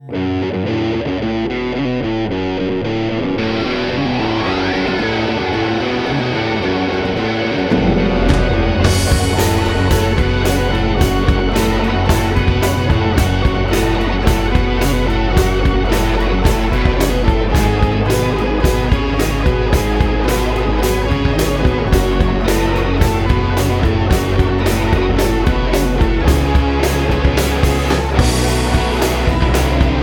Lead Guitar and Rhythm Guitar
Synthesizer Keys